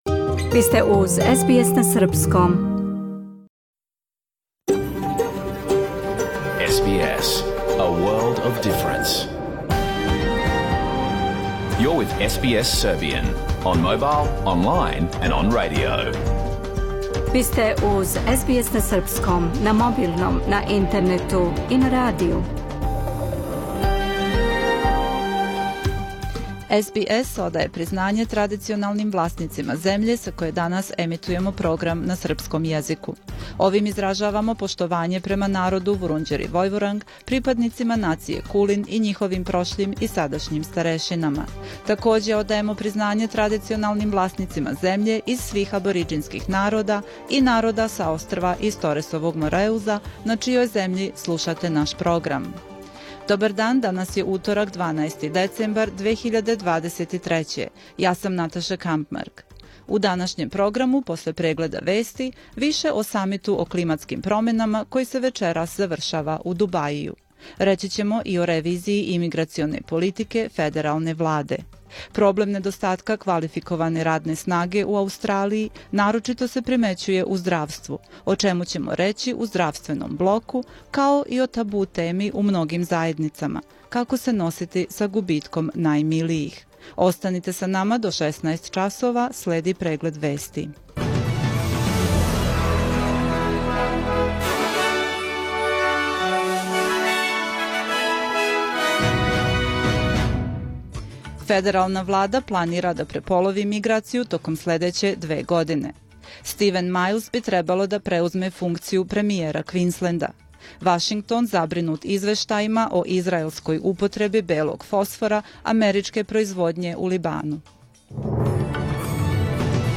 Serbian News Bulletin